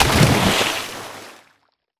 water_splash_object_body_01.wav